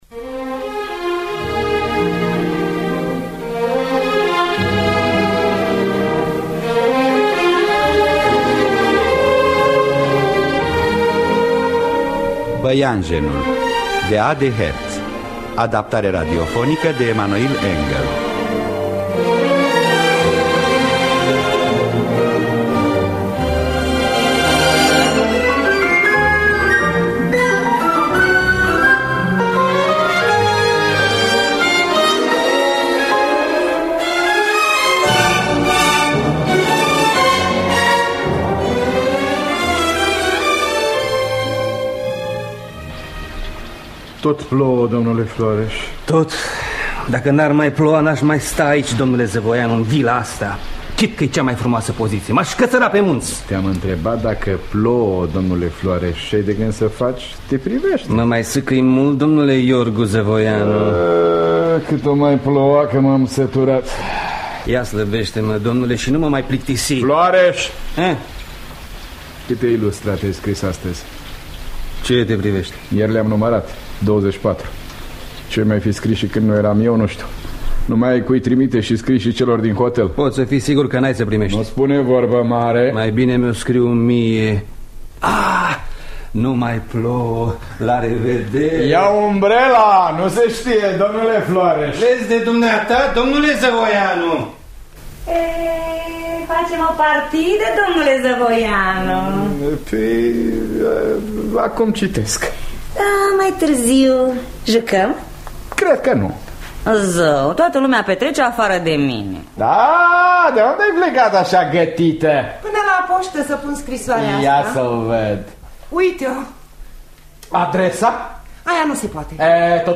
Adaptarea radiofonică de Emanoil Engel.